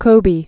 (kōbē, -bā)